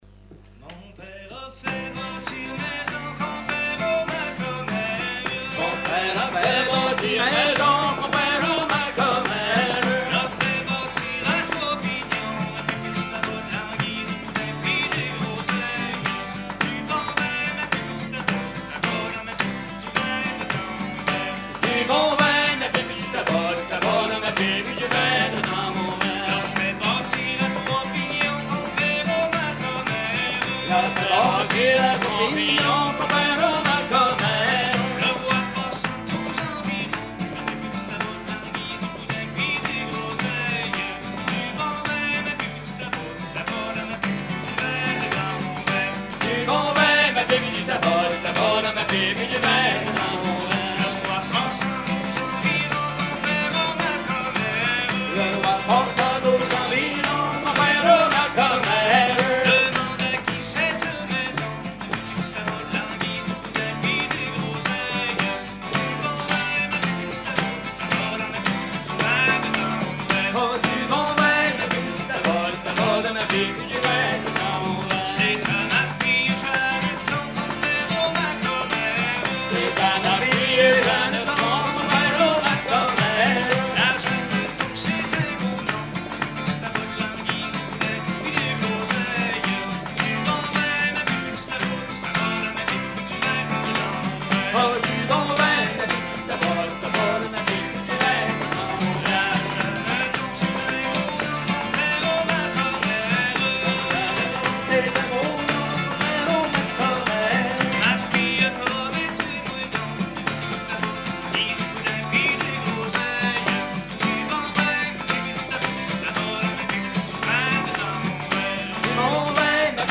Concert à la ferme du Vasais
Pièce musicale inédite